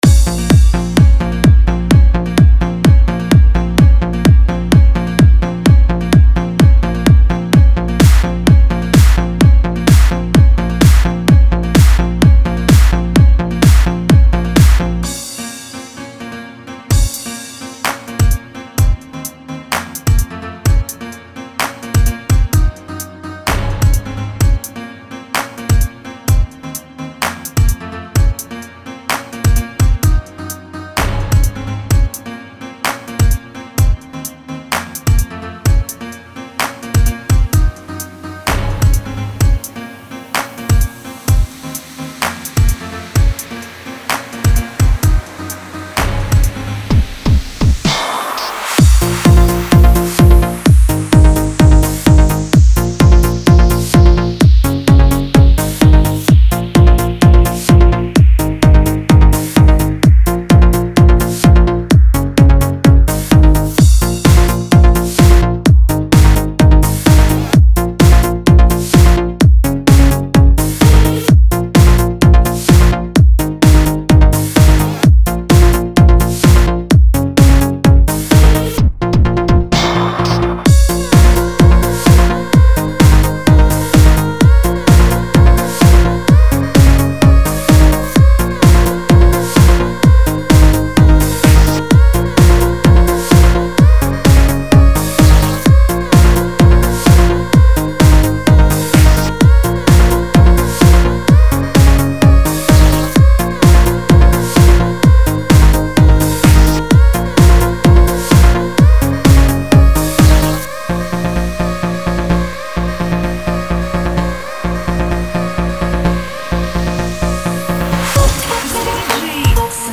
Категория: Trance